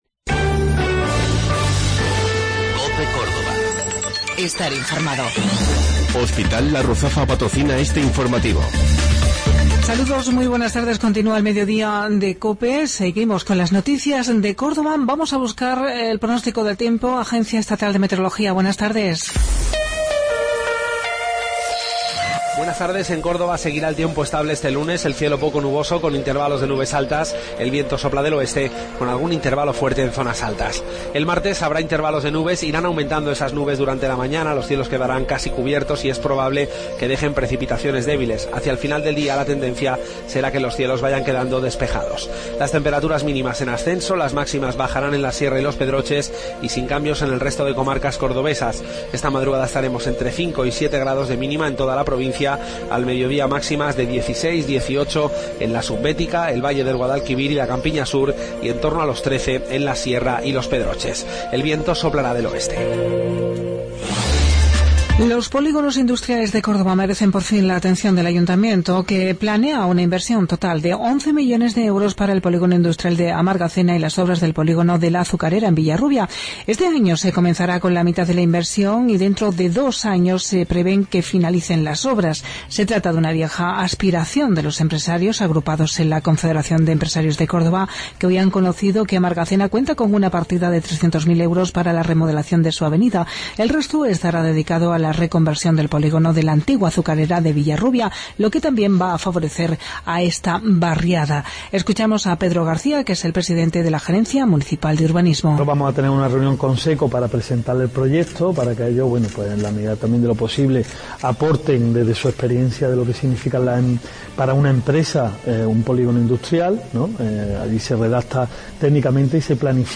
Mediodía Cope. Informativo Local, 6 de Febrero 2017